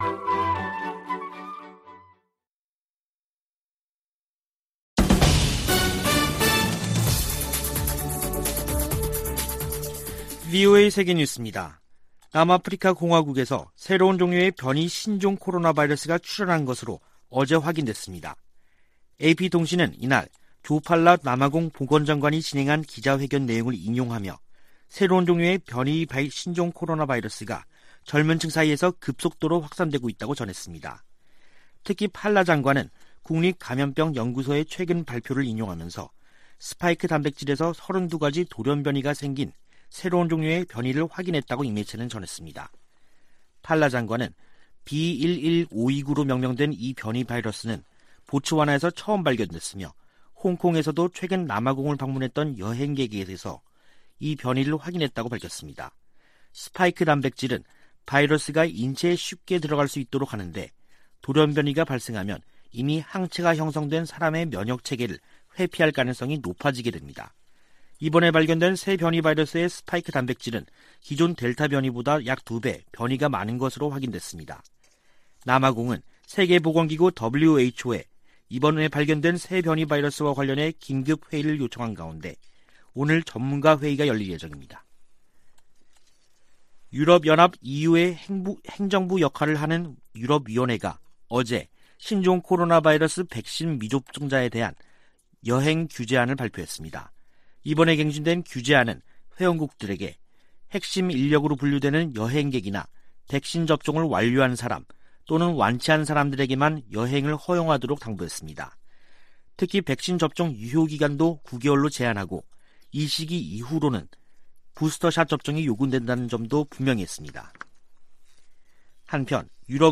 VOA 한국어 간판 뉴스 프로그램 '뉴스 투데이', 2021년 11월 26일 3부 방송입니다. 미 국무부는 북한의 계속된 핵 활동을 규탄하면서, 북한과의 비핵화 대화를 추구하고 있다고 밝혔습니다. 미국 상무부가 북한 유령회사에 미국과 다른 국가의 기술을 판매한 중국 기업 등, 국가안보에 위협이 되는 해외 기업들을 수출 규제 대상으로 지정했습니다. 북한 국영 고려항공이 또다시 유럽연합 회원국 내 운항이 엄격히 제한되는 항공사로 지정됐습니다.